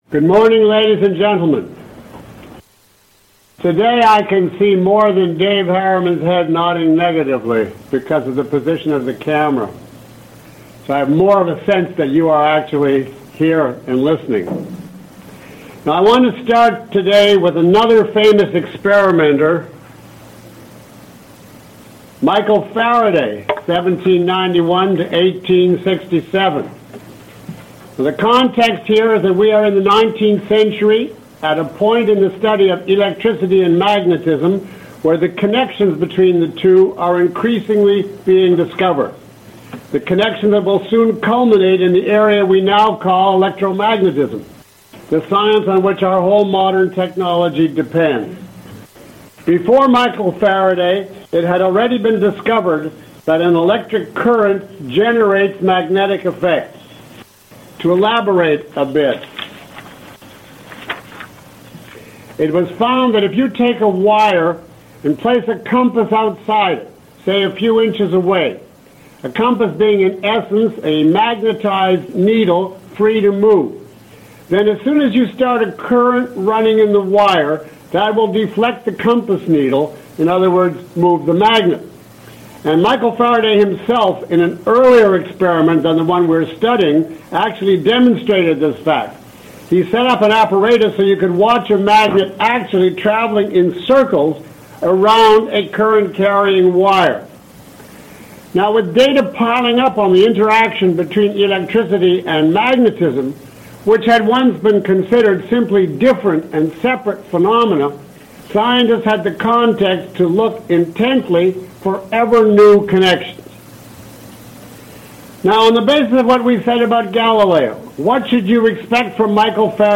Download Full Course Study Guide (PDF) Q&A Guide Below is a list of questions from the audience taken from this lecture, along with (approximate) time stamps. 2:00:07 Is the universe a plenum?